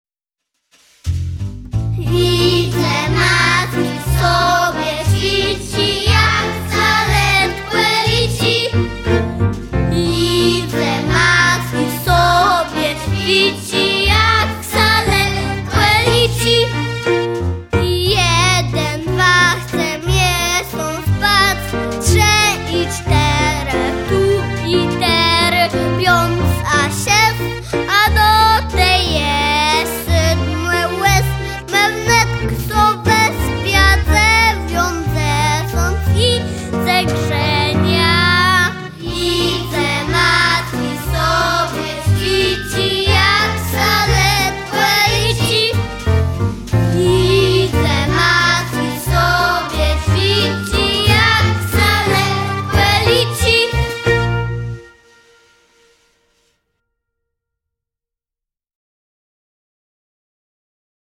Nagranie wykonania utworu tytuł